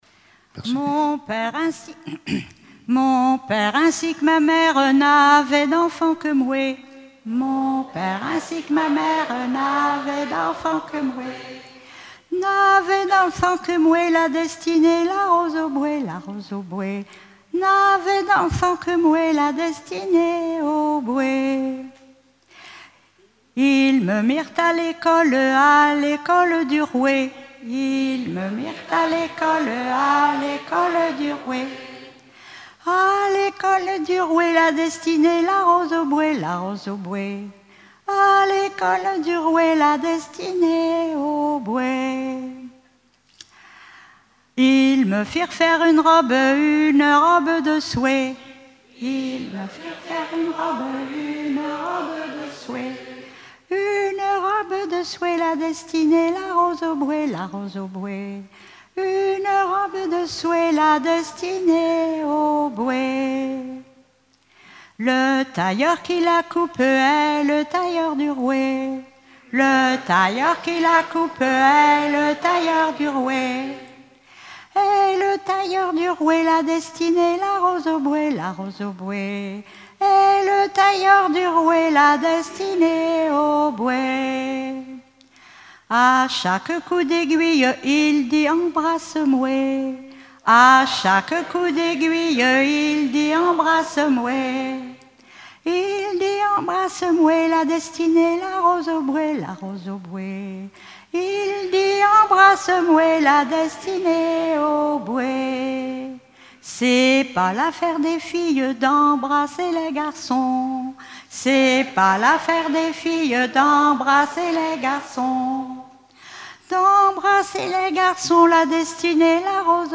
Festival de la chanson traditionnelle - chanteurs des cantons de Vendée
Pièce musicale inédite